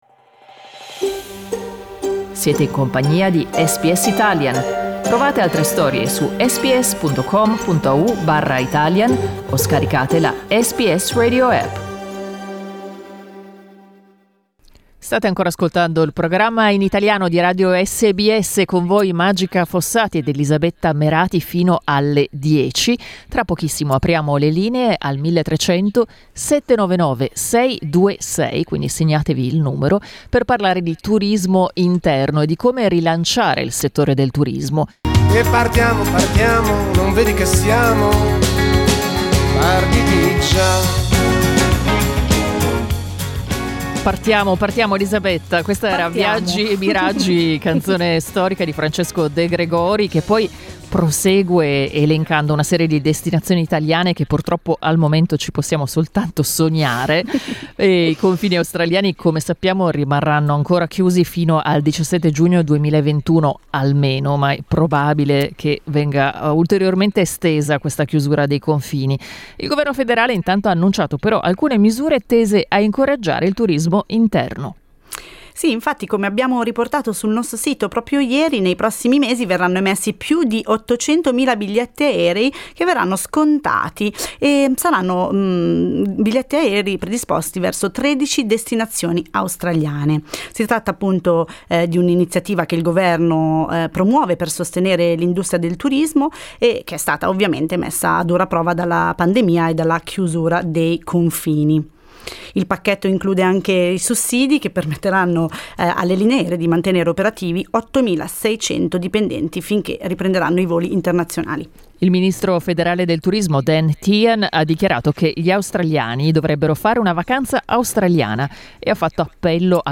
Il primo ministro Morrison ha annunciato tariffe aeree scontate per alcune mete australiane, ma basterà per ridare ossigeno al settore, messo a dura prova dalla pandemia? Vi abbiamo chiesto di dirci la vostra durante la diretta.